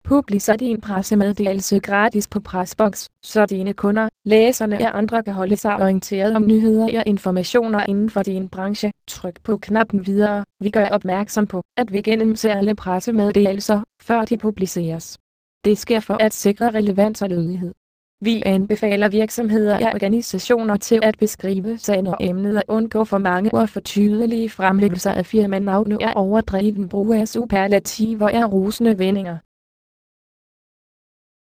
Texte de d�monstration lu par Nanna (Nuance RealSpeak; distribu� sur le site de Nextup Technology; femme; danois)